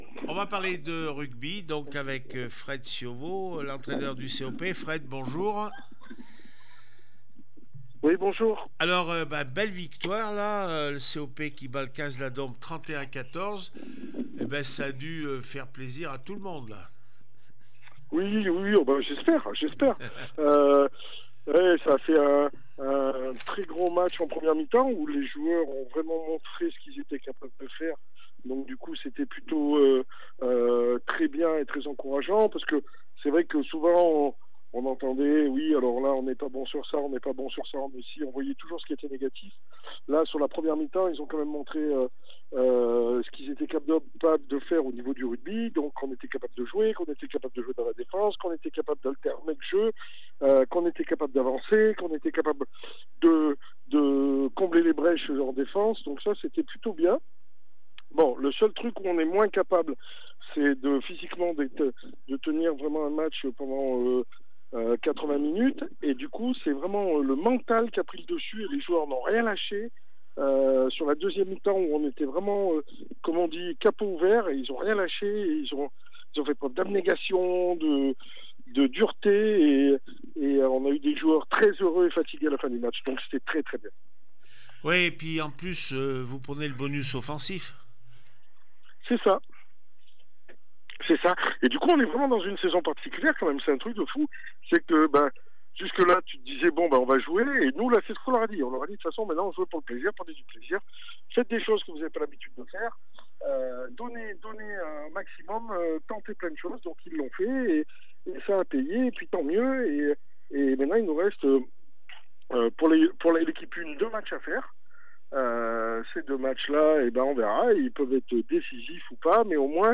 7 avril 2025   1 - Sport, 1 - Vos interviews